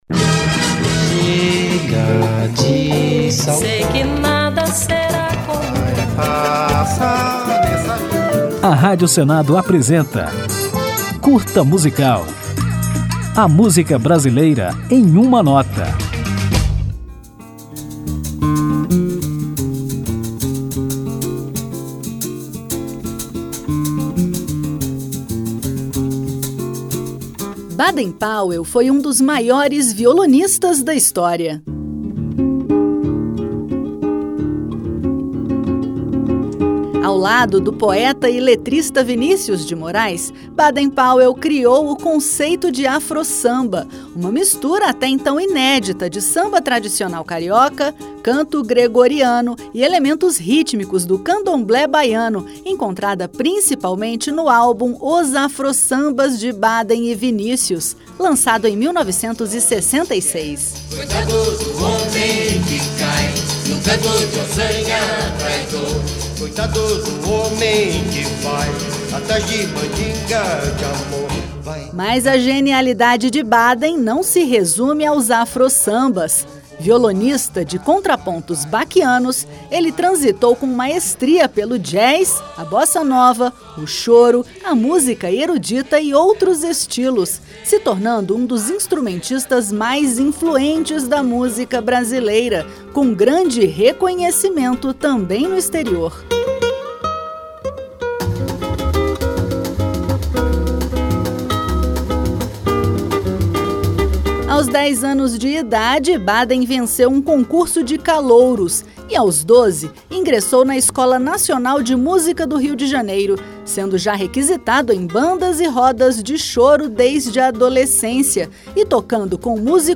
Depois de conhecermos um pouco da história de Baden Powell, vamos ouvi-lo na música Berimbau, considerada o primeiro Afro-Samba.